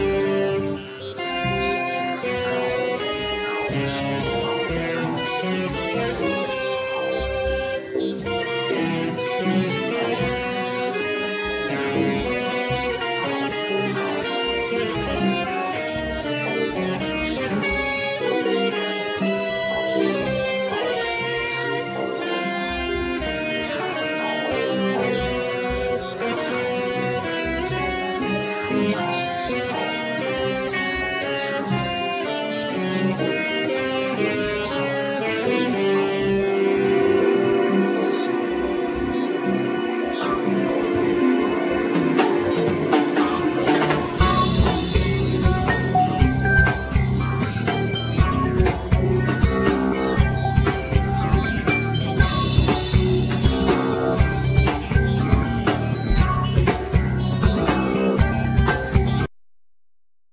Keyboards
Bass
Guitar
Cello
Clarinet
Drums
Flute
Violin